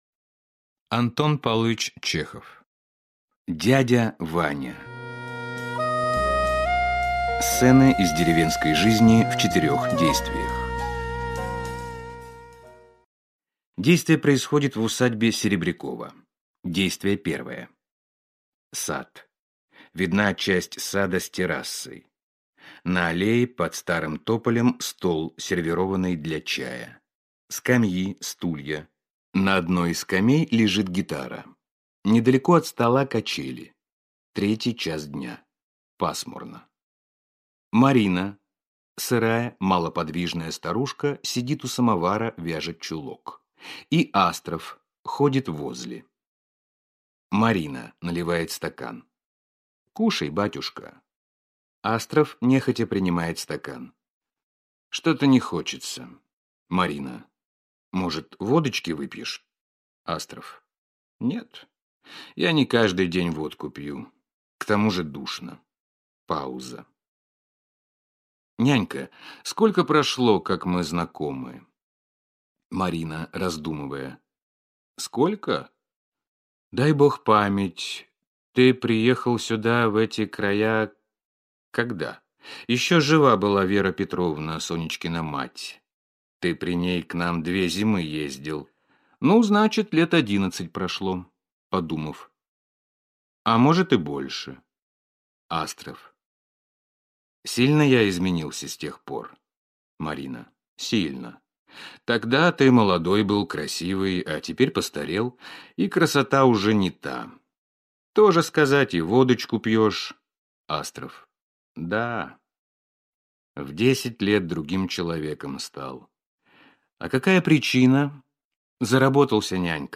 Аудиокнига Дядя Ваня | Библиотека аудиокниг